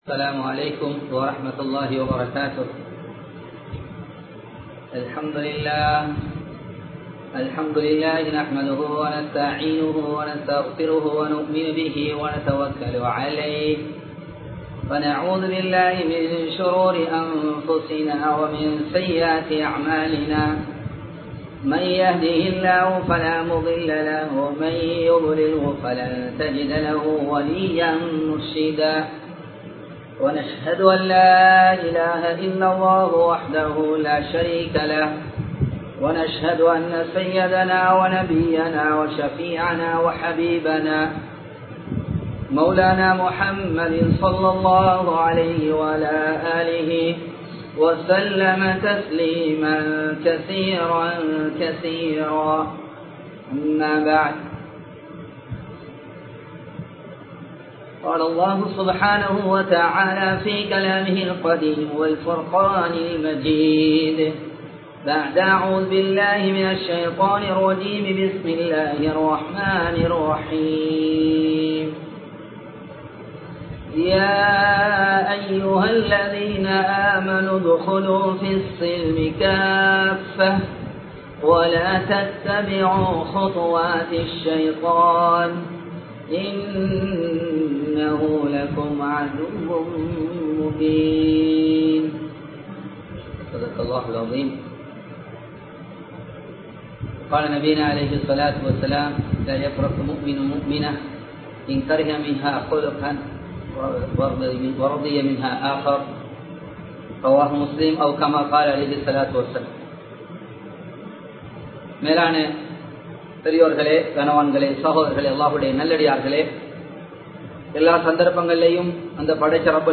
நிம்மதியான குடும்ப வாழ்க்கை வேண்டுமா? (Do you Need a Peacefull Family Life?) | Audio Bayans | All Ceylon Muslim Youth Community | Addalaichenai
Live Stream